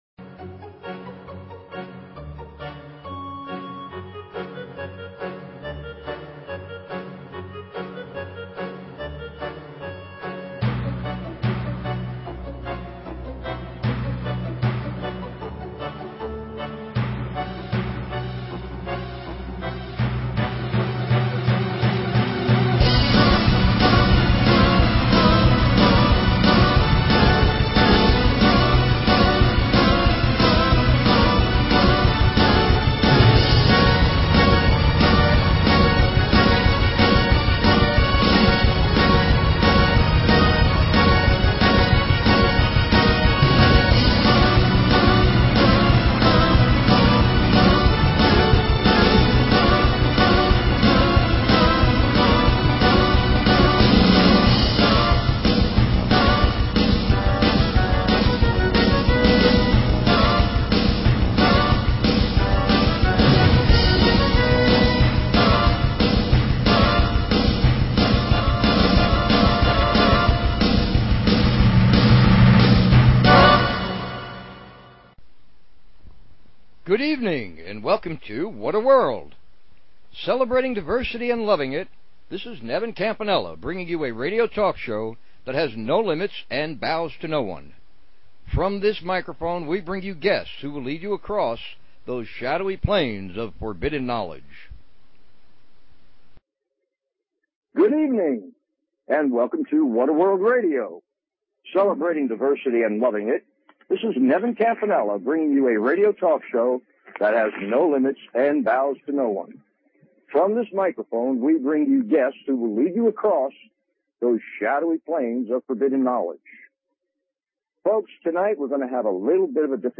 Talk Show Episode, Audio Podcast, What_A_World and Courtesy of BBS Radio on , show guests , about , categorized as